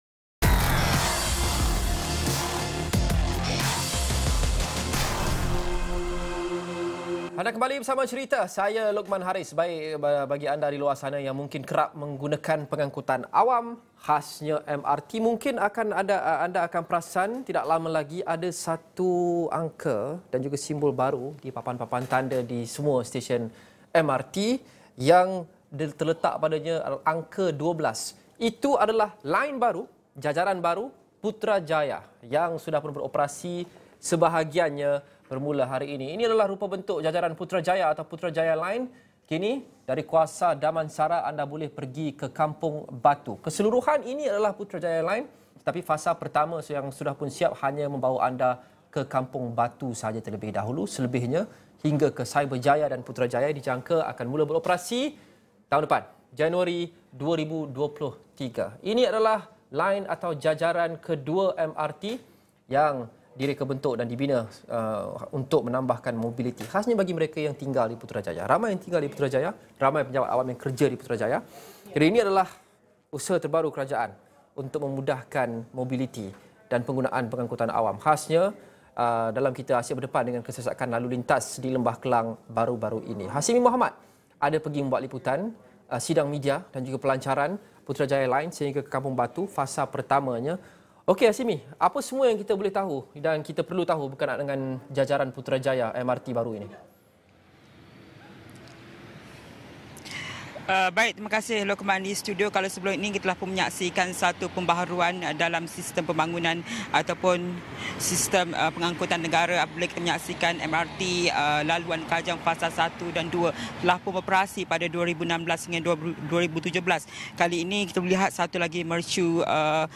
Wartawan Astro AWANI menceritakan kisah di sebalik sesuatu berita yang dilaporkan.